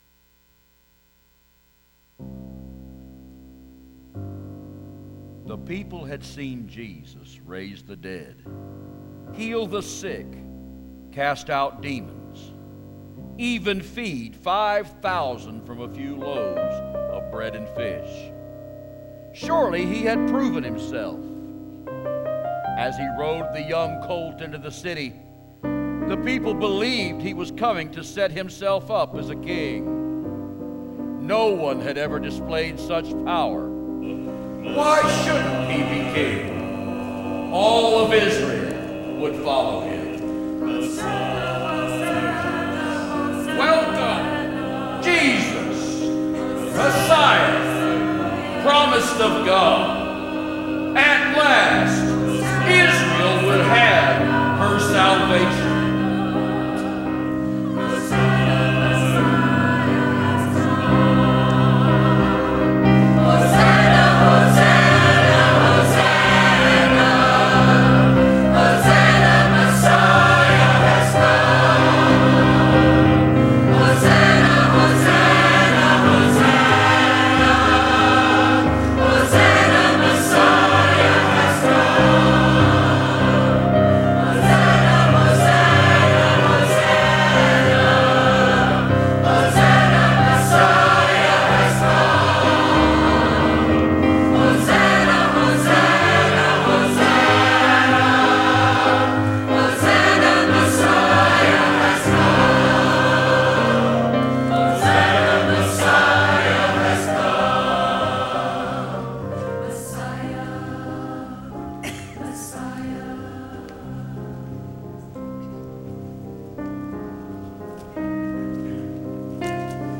Choir and Solo